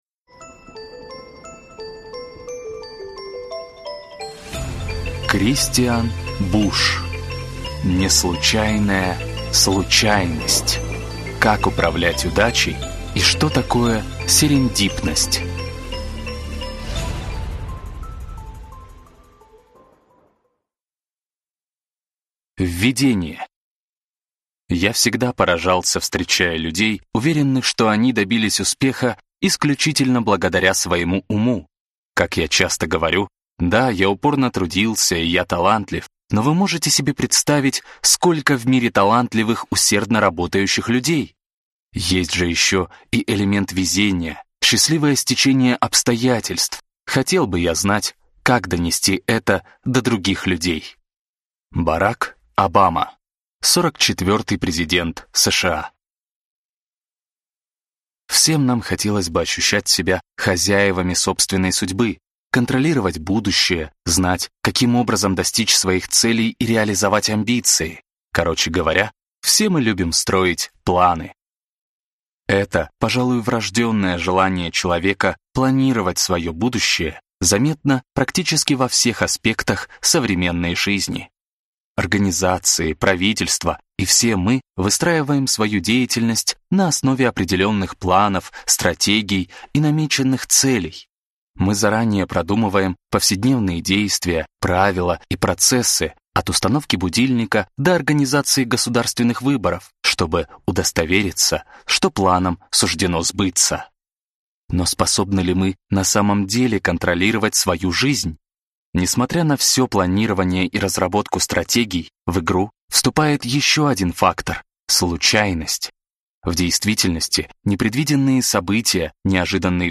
Аудиокнига Неслучайная случайность. Как управлять удачей и что такое серендипность | Библиотека аудиокниг